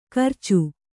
♪ karcu